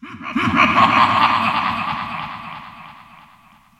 PixelPerfectionCE/assets/minecraft/sounds/mob/wither/hurt4.ogg at mc116
hurt4.ogg